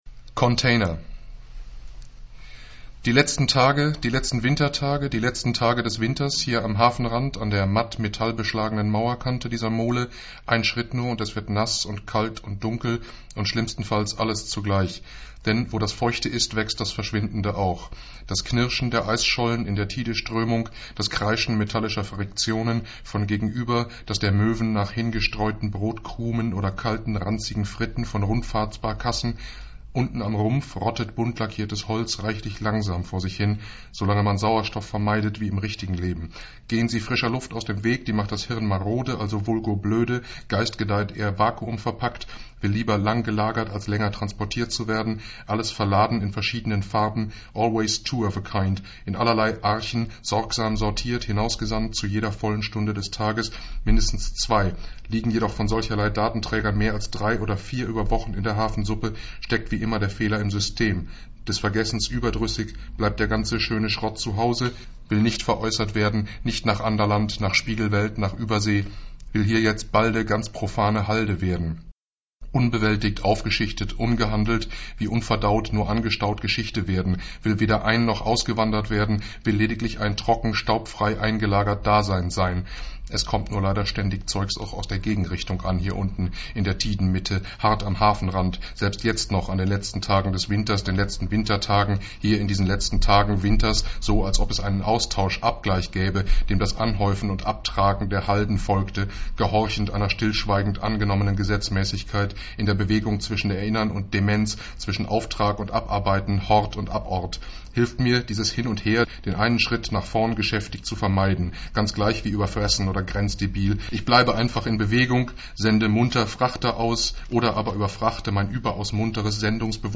container“, ein Fließtext, virtuos und hämmernd vorgelesen und doppelt so eindrucksvoll wie als Wortblock auf dem Papier.